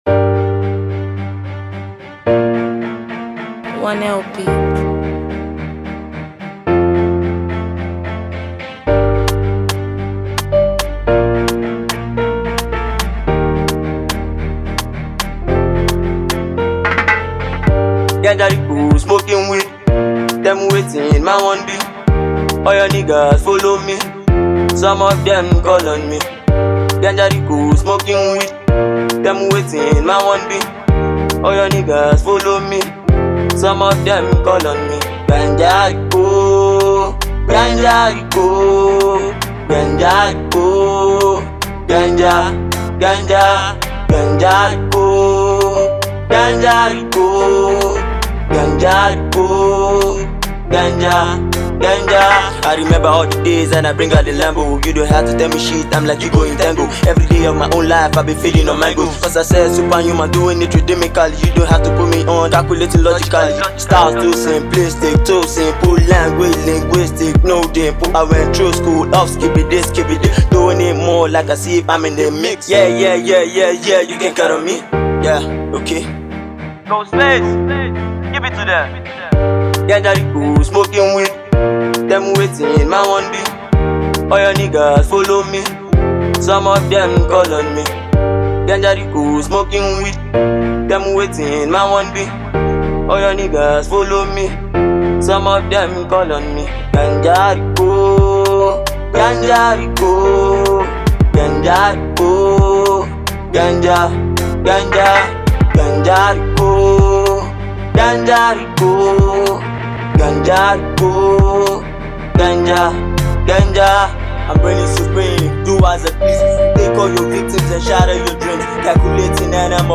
Nigerian Afro Act